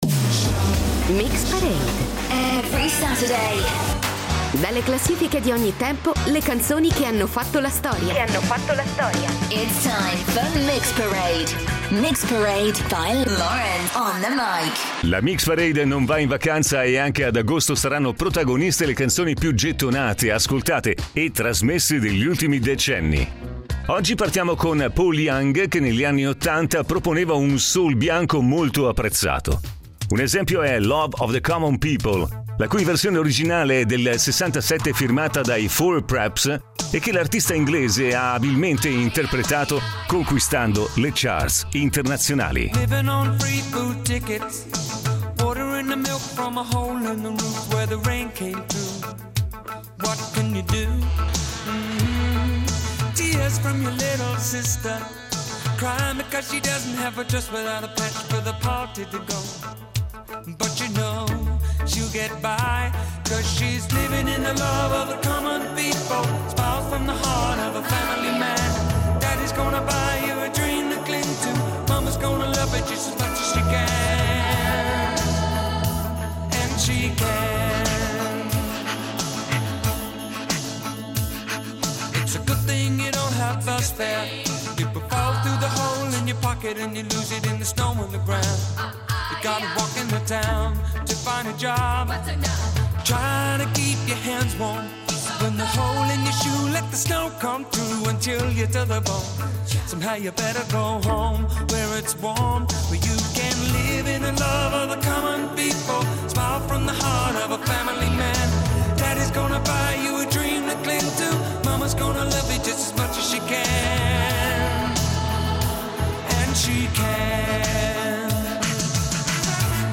Accanto ai grandi classici storici della musica pop e rock
il classico folk-pop